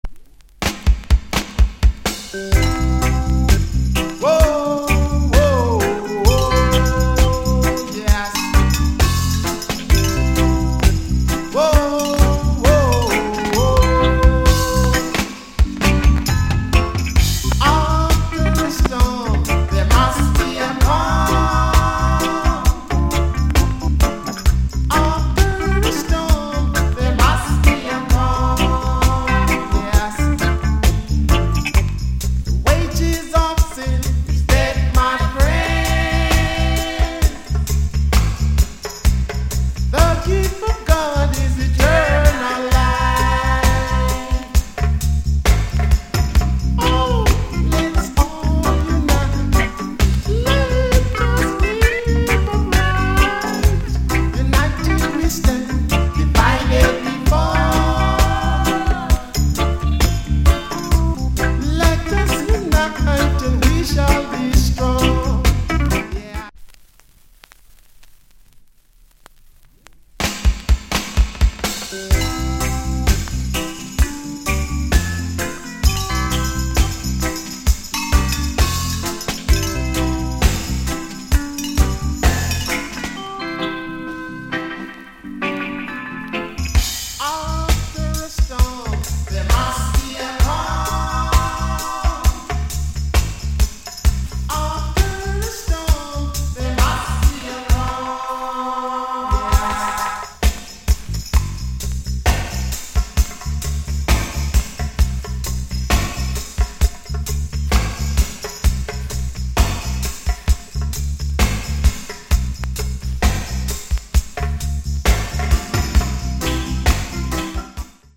Nice Vo with Bongo